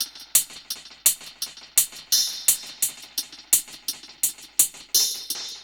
Index of /musicradar/dub-drums-samples/85bpm
Db_DrumKitC_Wet_EchoHats_85-02.wav